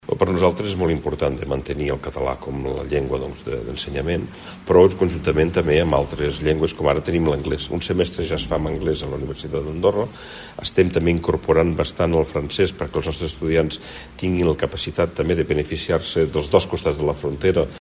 Minoves ha fet aquestes declaracions durant la roda de premsa posterior a la inauguració del Seminari CRUE-UdA, celebrat a Sant Julià de Lòria.